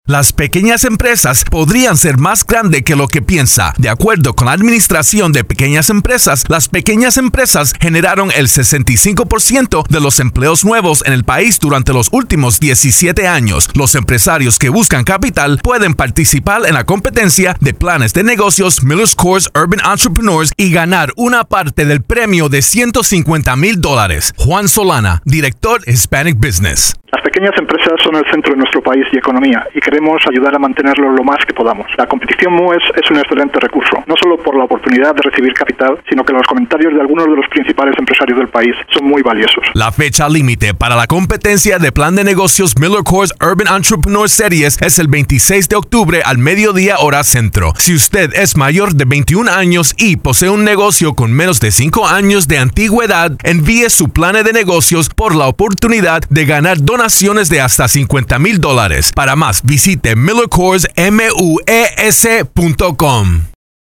October 18, 2012Posted in: Audio News Release